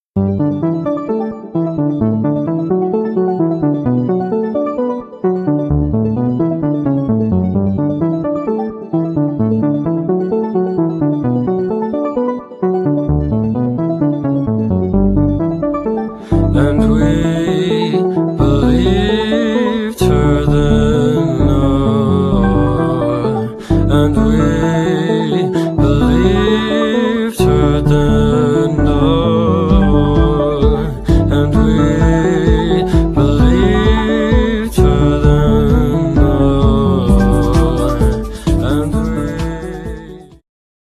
inspirowana elektronicznym euro-popem